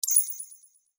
Epic Holographic User Interface Click 7.wav